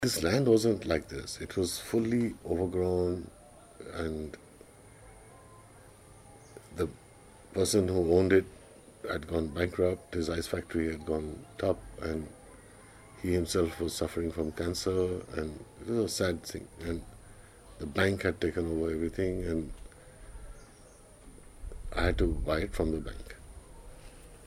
Excerpts from a conversation